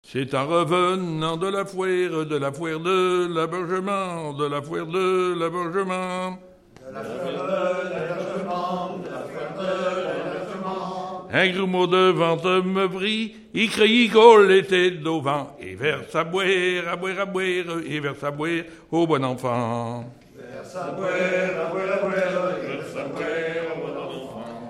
Genre laisse
Enregistrement de chansons
Pièce musicale inédite